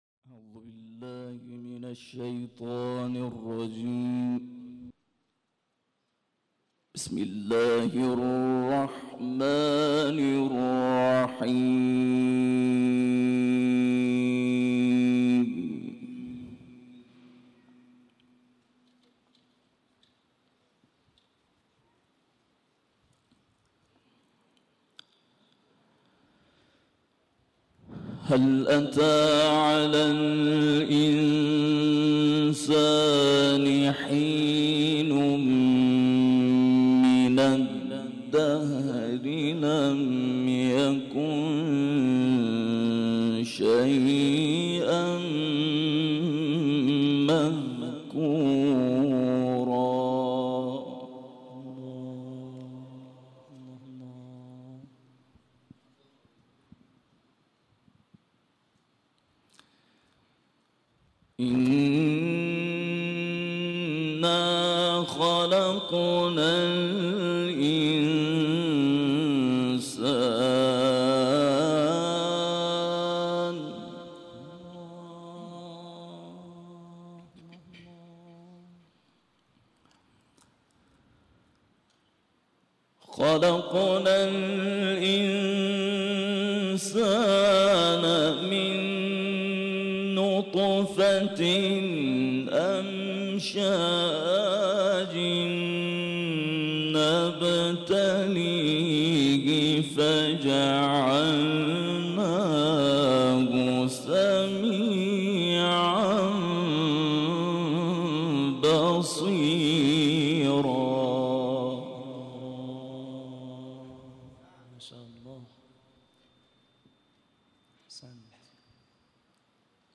در کرسی تلاوت و تفسیر قرآن کریم شهرستان علی آباد کتول